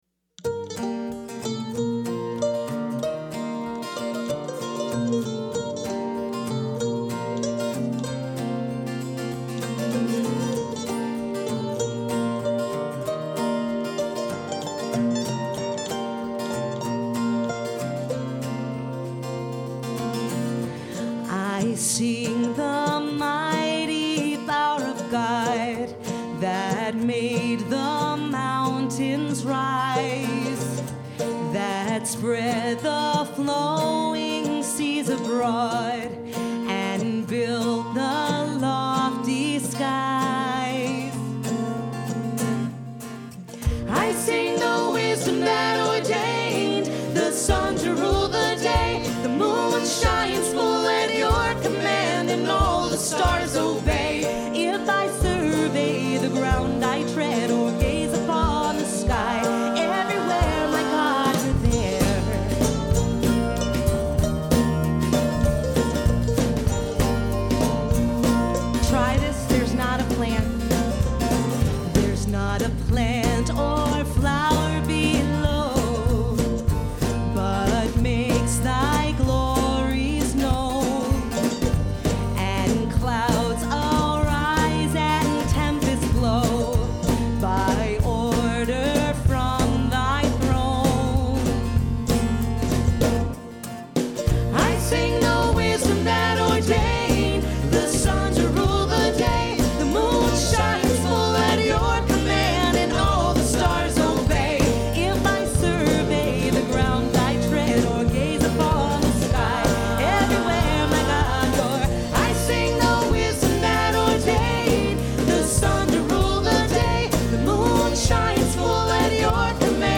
Wayfaring Stranger
Performed live at Terra Nova - Troy on 2/28/10.